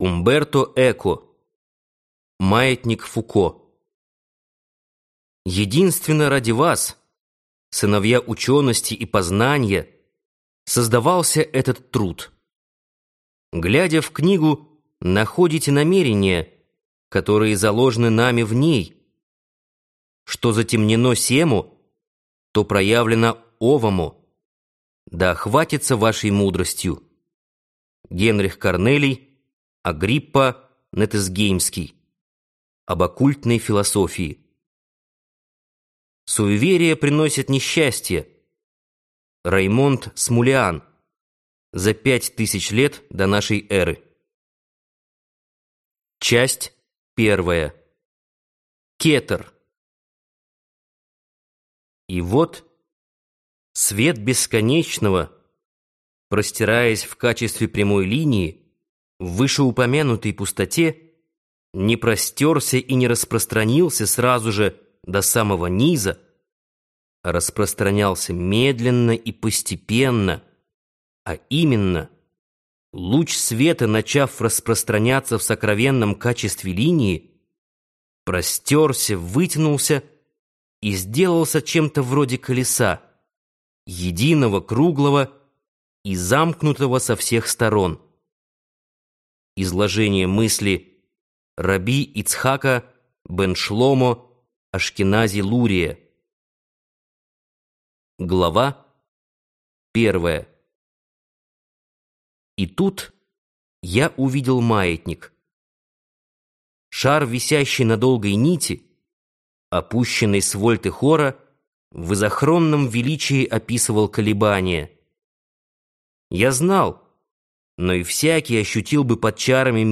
Аудиокнига Маятник Фуко - купить, скачать и слушать онлайн | КнигоПоиск